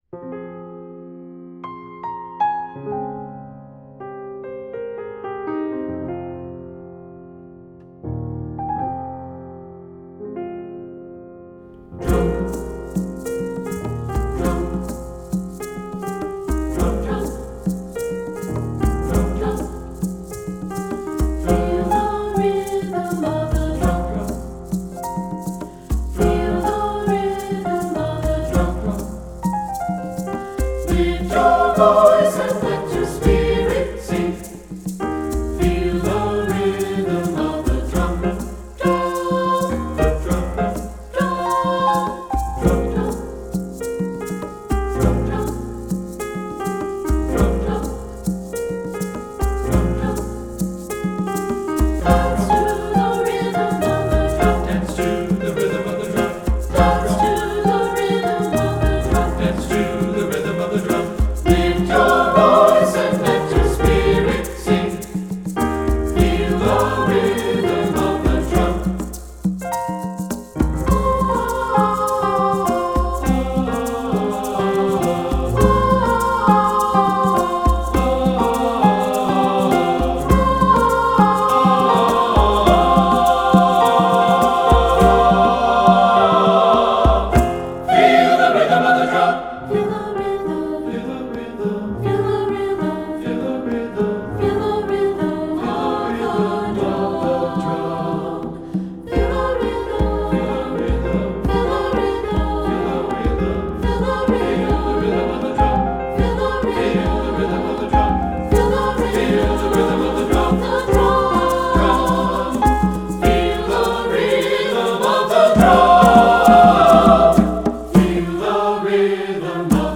Voicing: SATB, Piano and Percussion